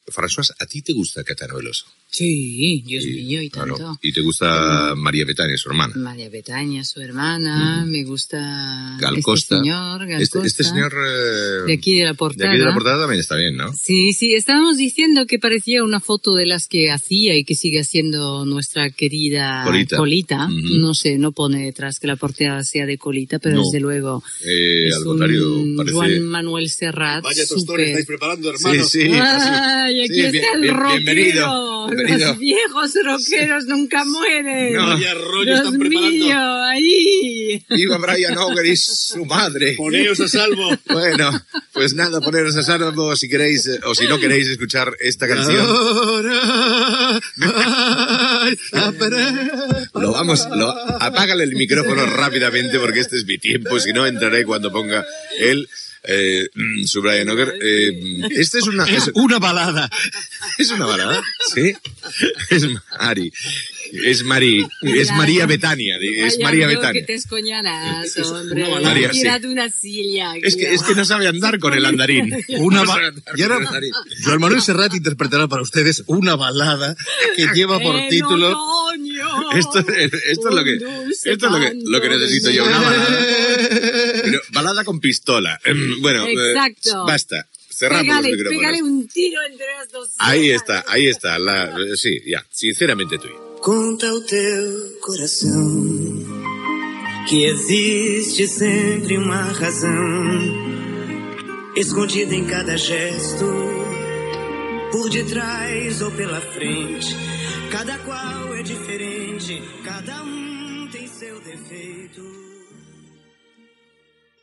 Diàleg distès entre els presentadors mentre fan la presentació d'un tema musical.
Musical
FM